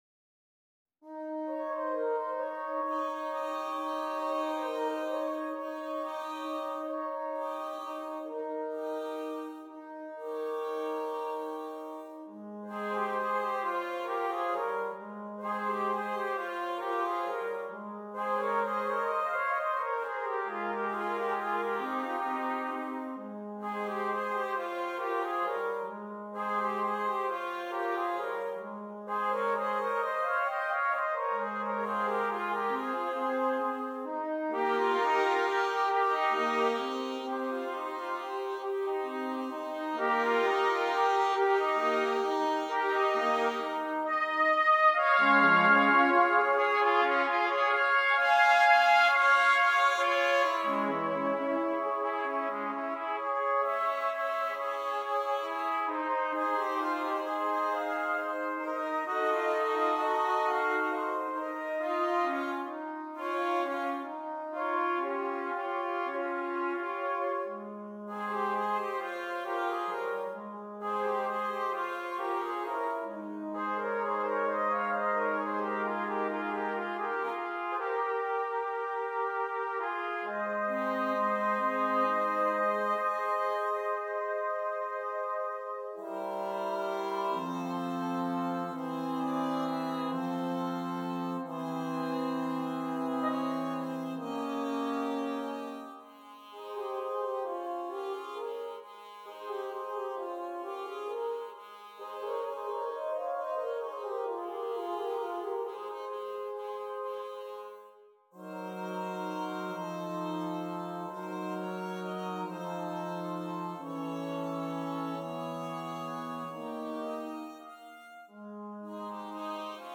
Brass
6 Trumpets